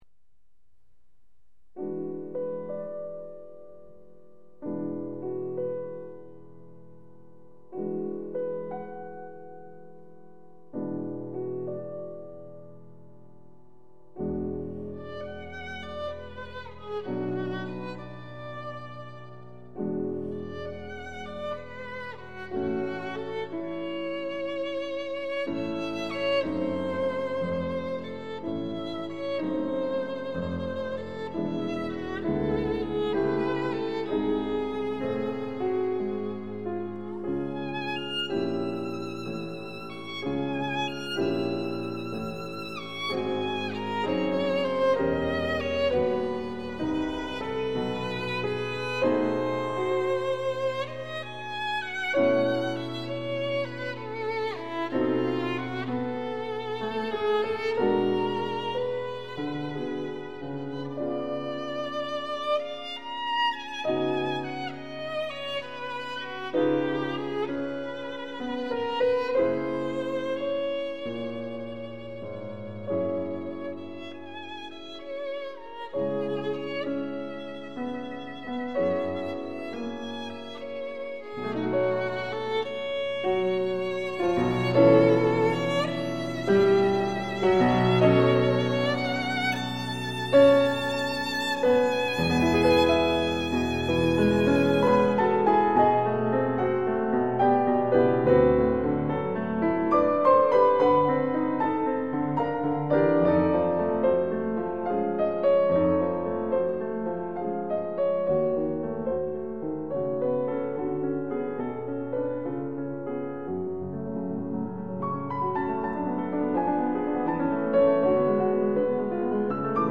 ★ 著名工程師採單點錄音，高傳真效果完美呈現！
★ 令人顫抖著迷的小提琴美音之最，發燒必備珍品！
Sonata for Violin and Piano